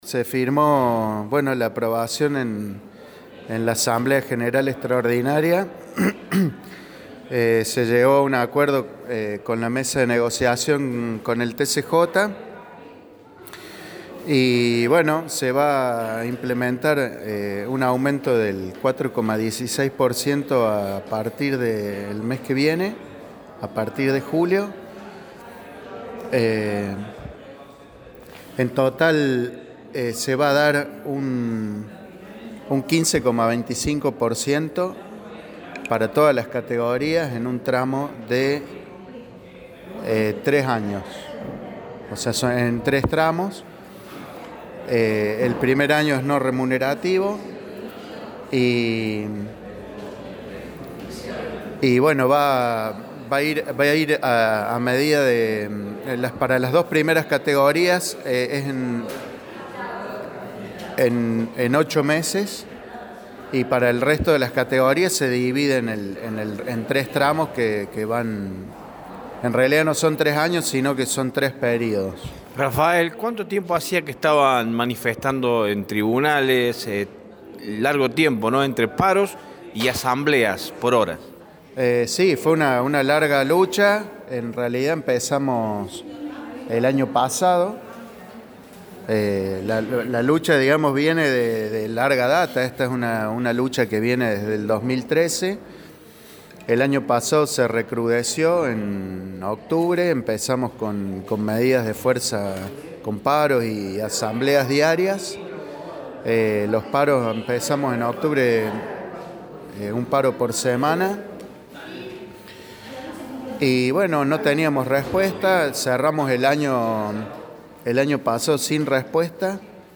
en diálogo con Radio Show explicó